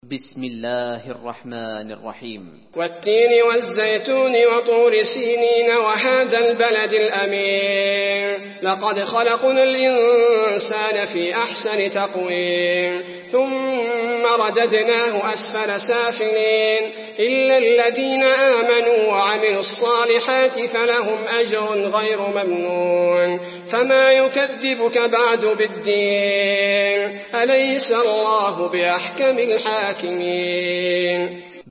المكان: المسجد النبوي التين